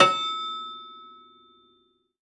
53w-pno10-E4.wav